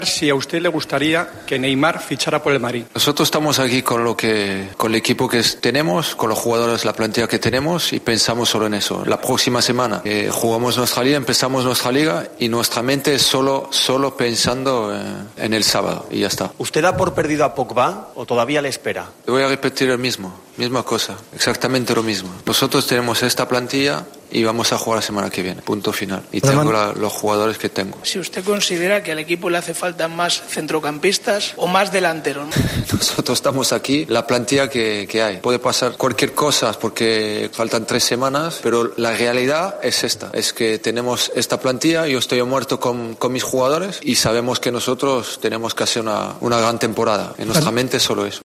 "Son jugadores del equipo James y Bale, están inscritos y contamos con todos los jugadores que están aquí aunque puede pasar cualquier cosa hasta el 31", aseguró en rueda de prensa tras el empate a dos tantos frente al Roma.